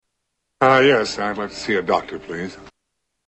Tags: Comedians Leslie Nielsen Leslie Nielsen Clips Actor Leslie Nielsen Soundboard